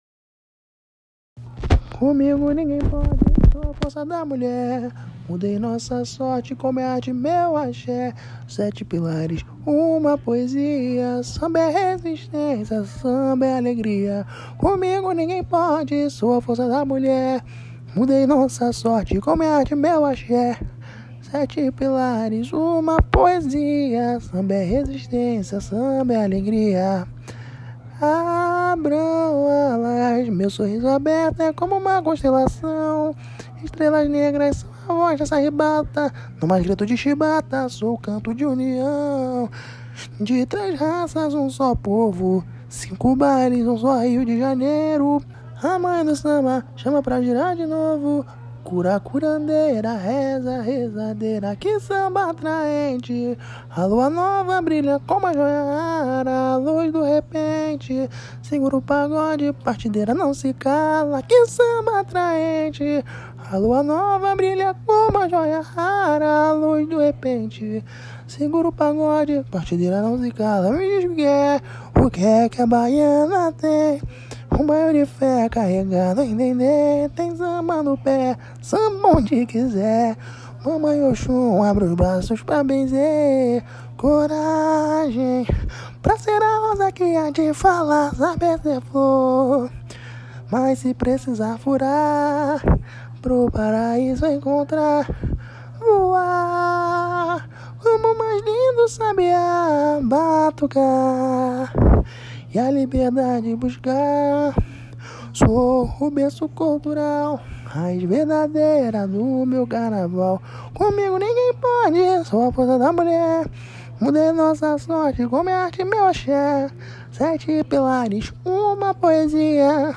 Samba  01